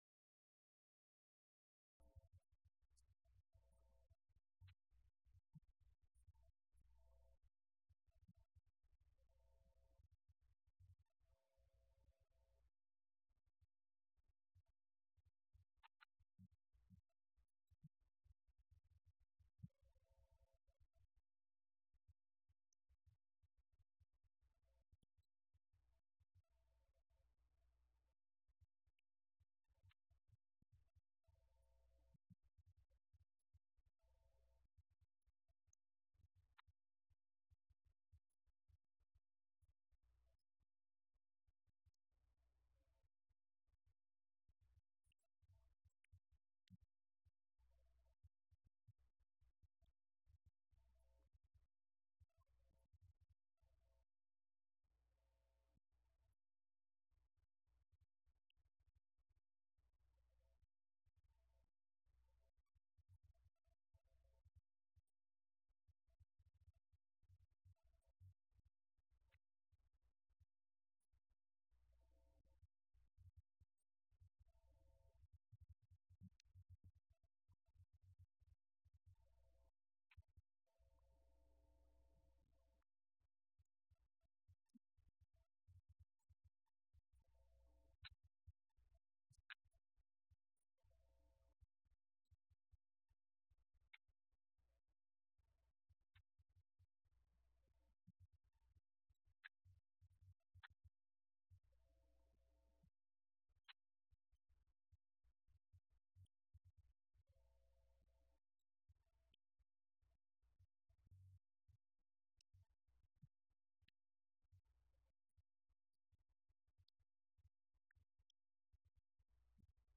Event: 17th Annual Schertz Lectures Theme/Title: Studies in Job